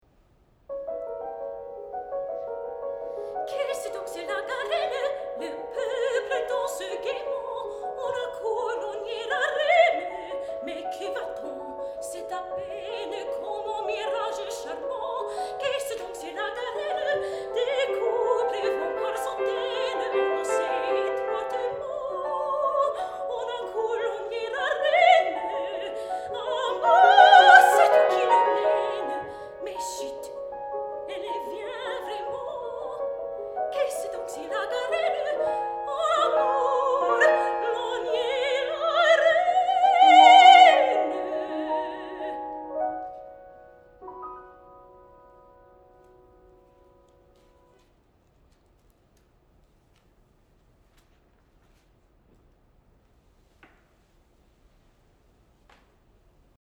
Piano
Mezzo-Soprano
Cello
Oboe